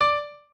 piano2_8.ogg